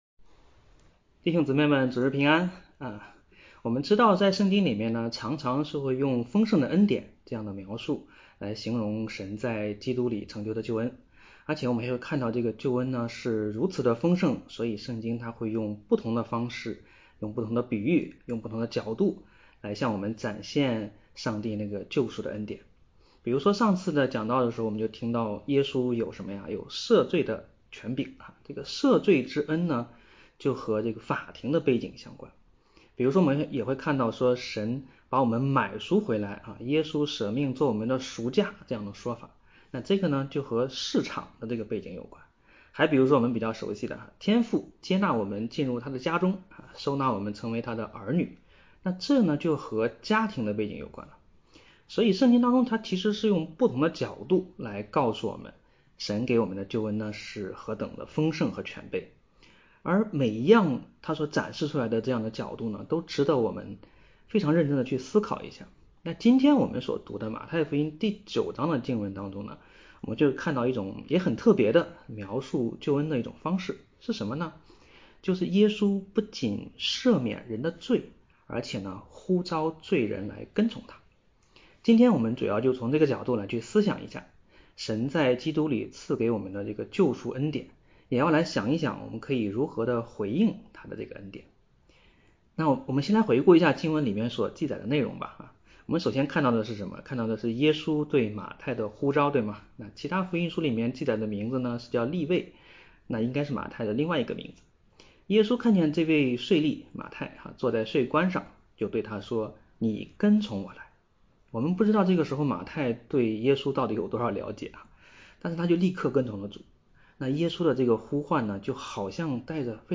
北京守望教会2025年3月9日主日敬拜程序